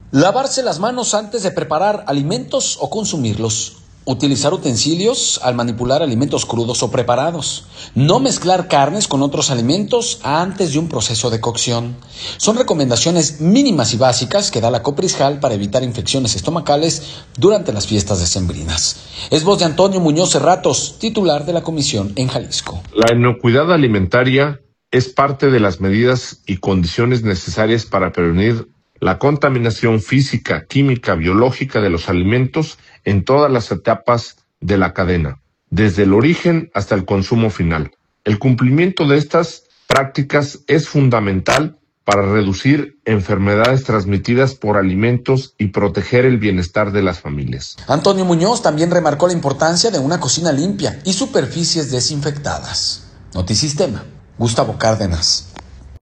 Es voz de Antonio Muñoz Serratos, titular de la comisión en Jalisco.